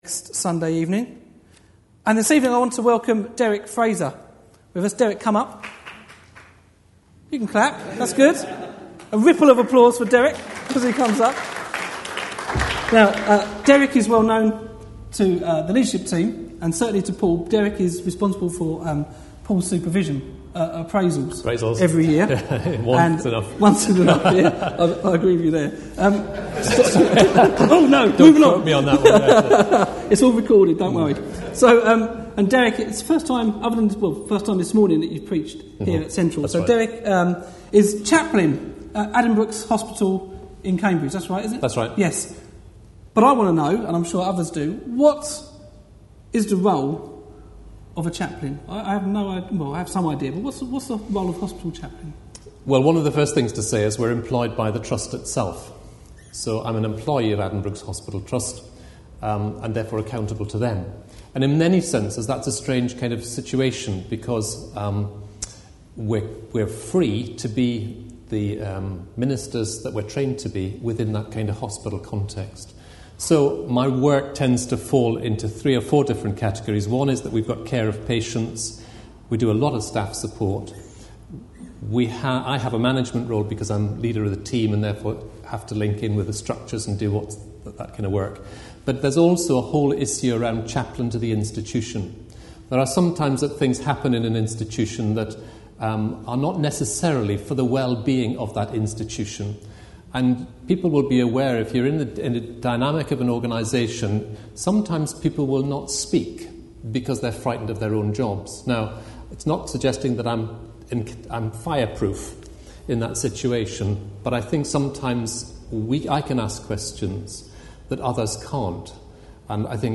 105th Anniversary Service
A sermon preached on 16th May, 2010.